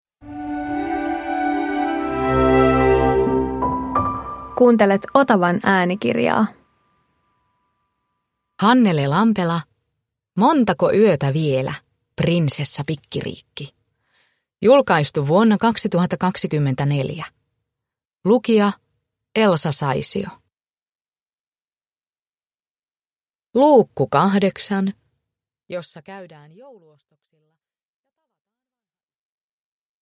Montako yötä vielä, Prinsessa Pikkiriikki 8 – Ljudbok